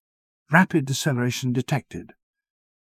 rapid-deceleration-detected.wav